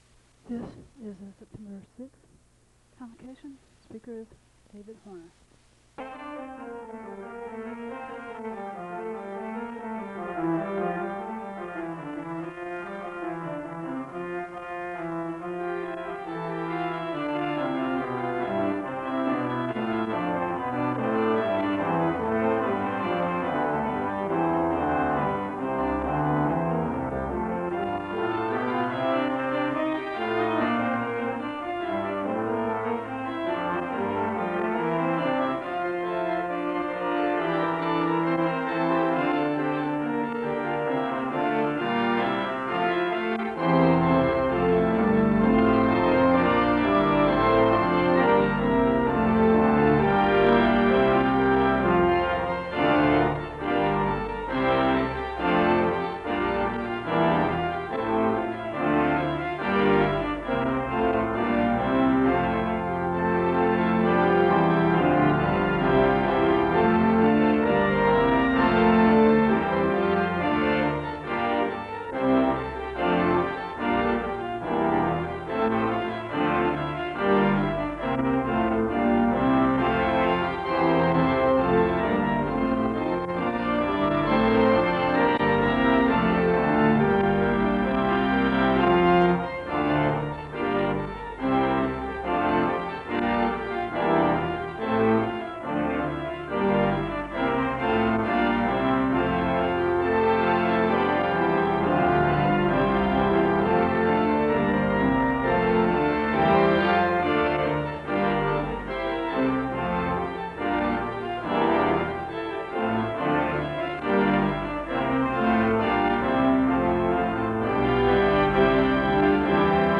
The service begins with organ music (00:00-04:50).
The choir sings the anthem (06:01-09:12).